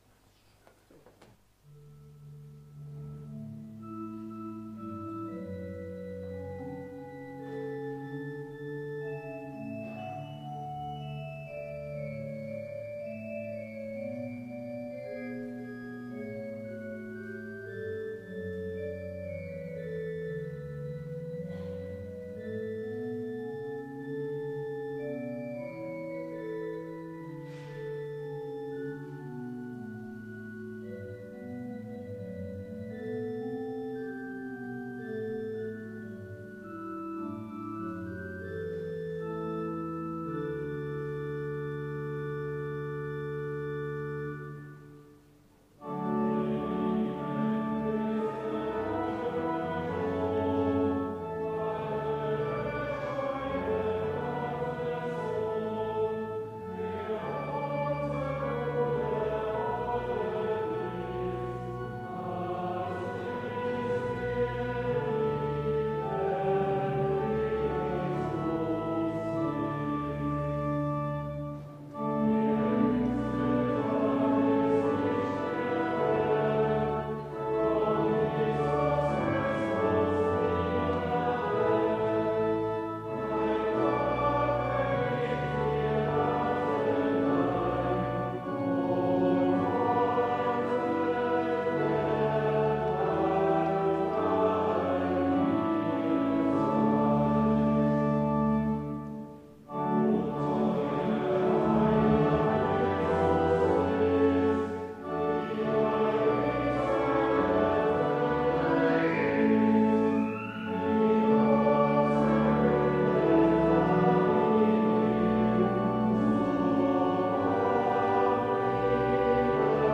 Audiomitschnitt unseres Gottesdienstes vom 1.Avent 2024